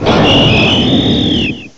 cry_not_silvally.aif